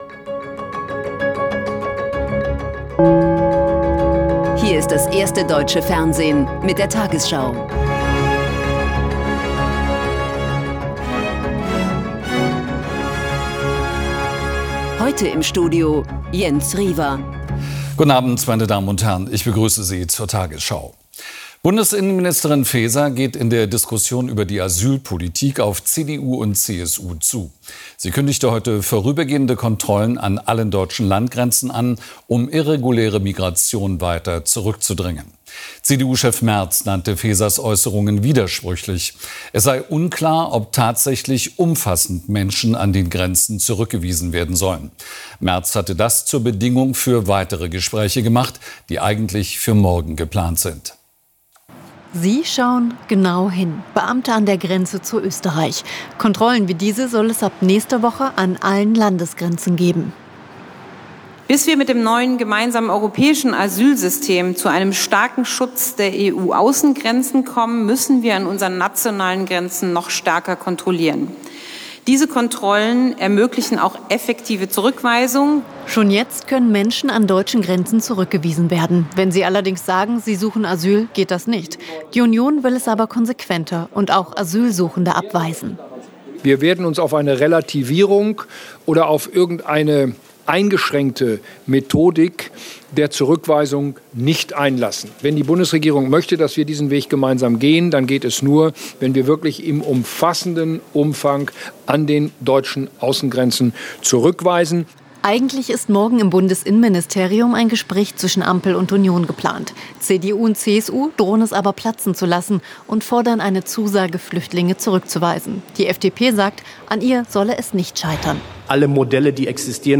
Die 20 Uhr Nachrichten von heute zum Nachhören. Hier findet ihr immer, was am Tag aktuell und wichtig ist in den News.